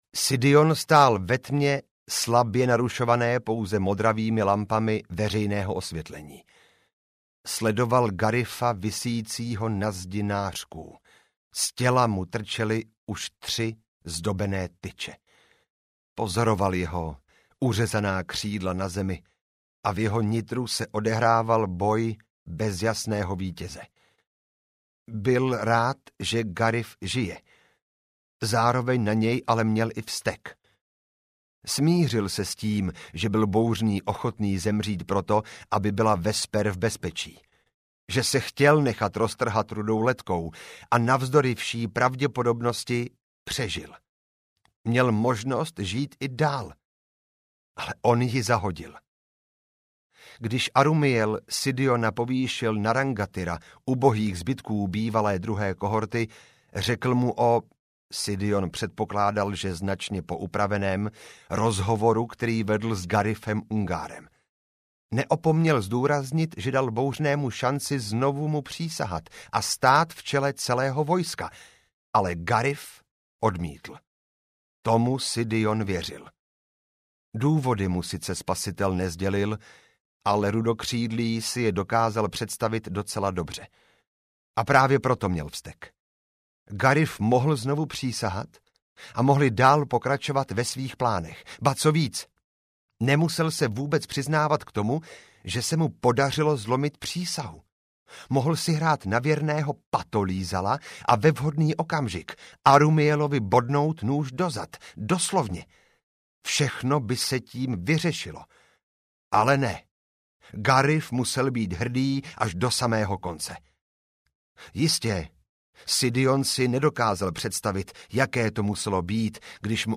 Bouřný úsvit audiokniha
Ukázka z knihy